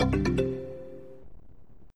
MH - Item Found.wav